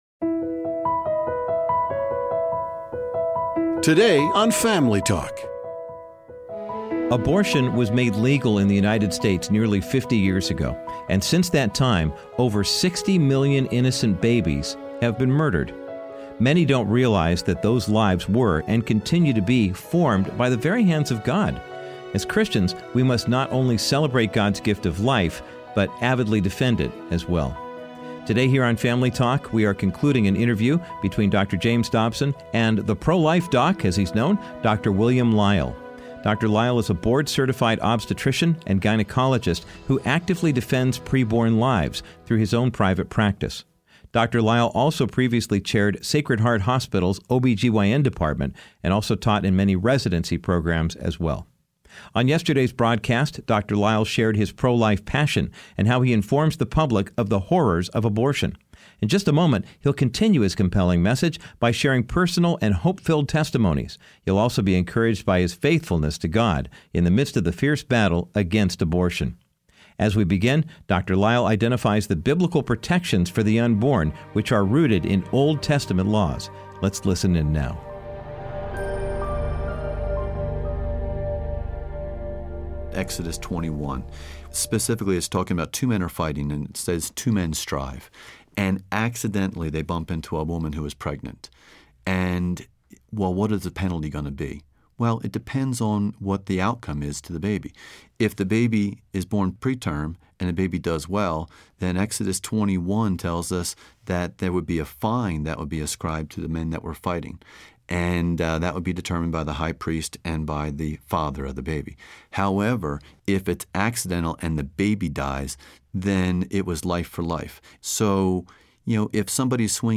Dr. Dobson concludes his interview with the pro-life Doc